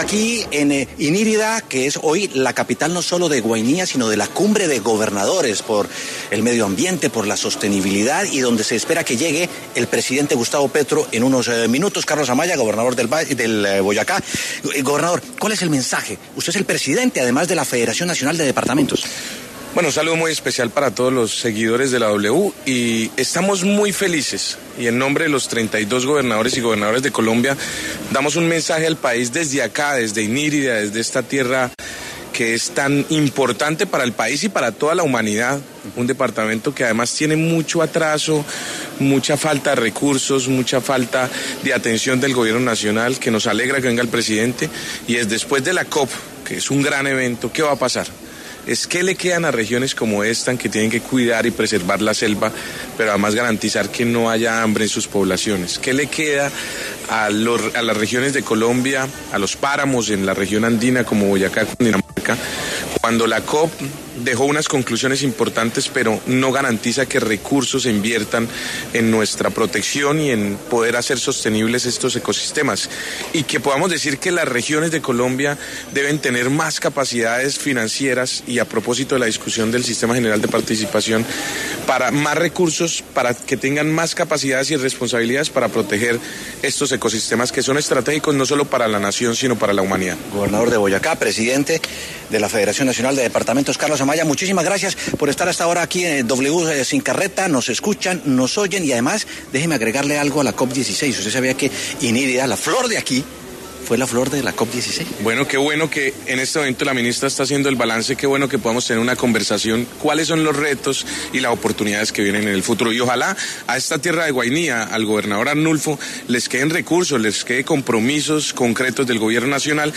Carlos Amaya, gobernador de Boyacá y presidente de la Federación Nacional de Departamentos, habló con W Sin Carreta a propósito de la Cumbre de Gobernadores que se desarrollará en Inírida y en la que se abordarán temas de sostenibilidad y medioambiente.